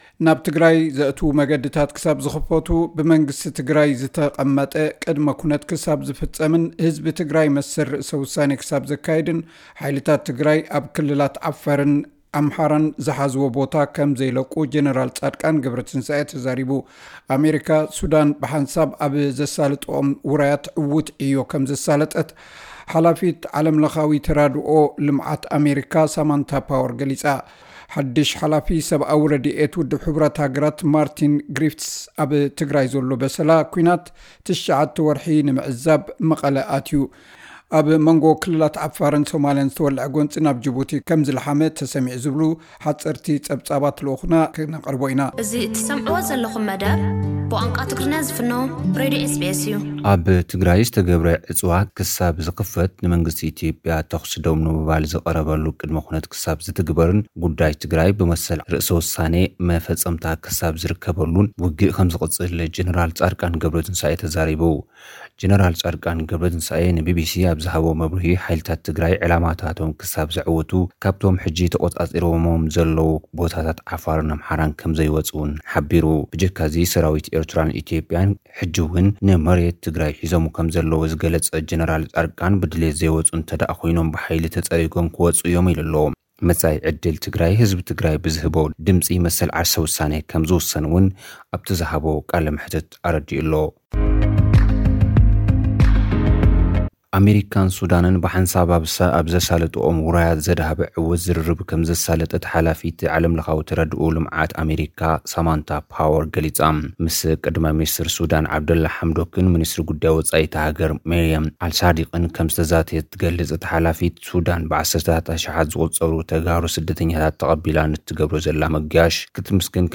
ሓዱሽ ሓላፊ ሰብኣዊ ረድኤት ውድብ ሕቡራት ሃገራት ማርቲን ግሪፊትስ ኣብ ትግራይ ዘሎ በሰላ ኩናት 9 ወርሒ ንምዕዛብ መቐለ ኣትዩ። ኣብ ሞንጎ ክልላት ዓፋርን ሶማሊን ዝተወለዐ ጎንጺ ናብ ጁቡቲ ከም ዝለሓመ ተሰሚዑ። ዝብሉ ሓጸርቲ ጸብጻባት ልኡኽና ክቐርብዩ።